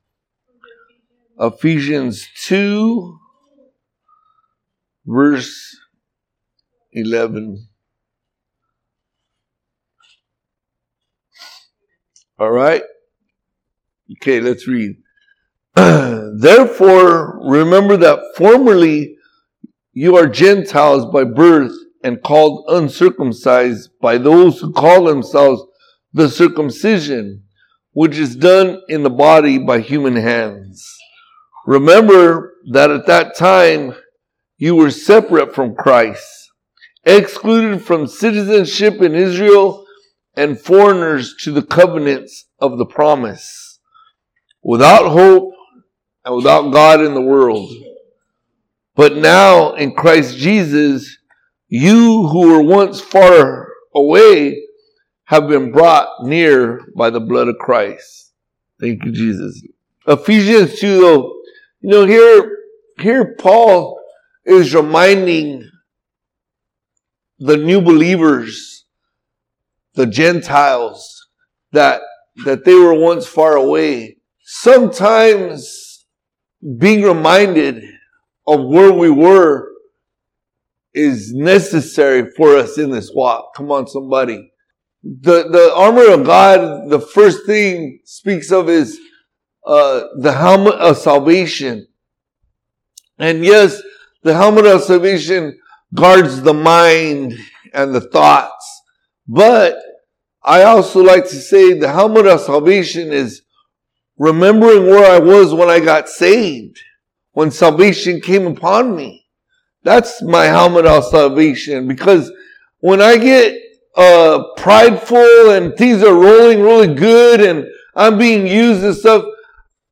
Podcast (piru-community-church-sermons): Play in new window | Download